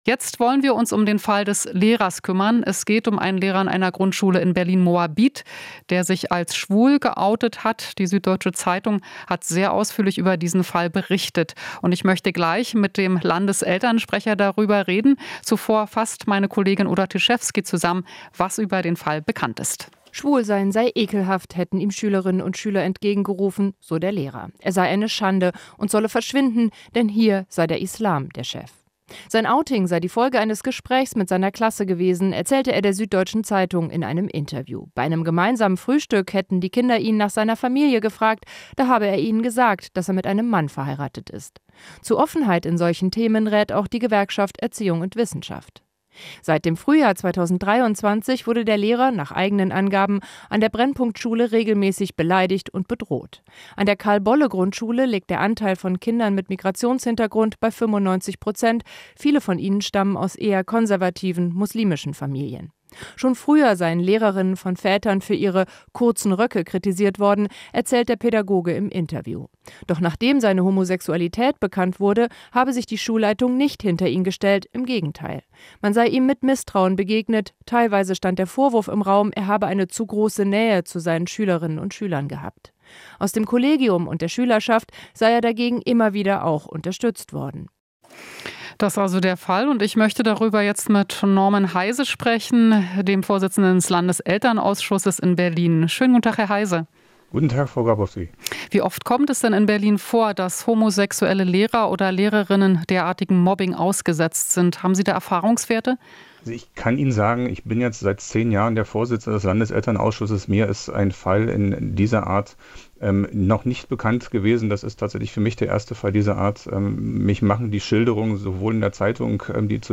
Interview - Mobbing nach Lehrer-Outing: "Für mich der erste Fall dieser Art"